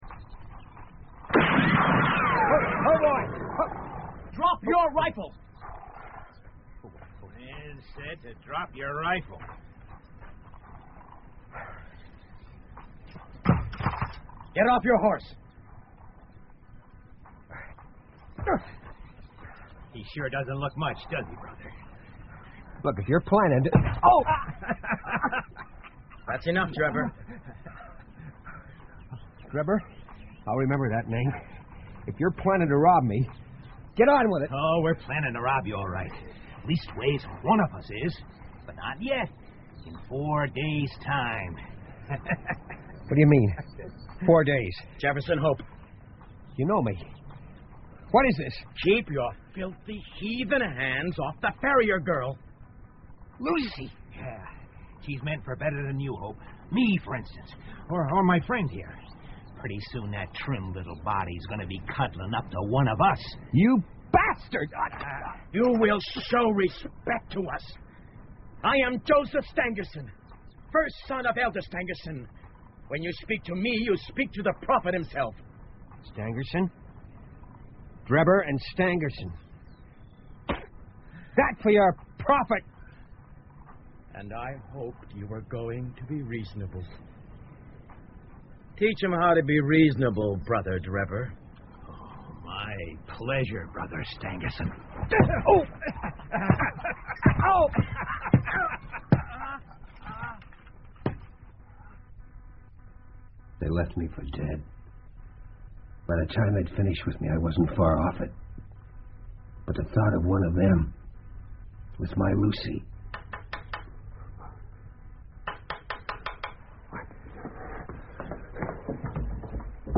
福尔摩斯广播剧 A Study In Scarlet 血字的研究 19 听力文件下载—在线英语听力室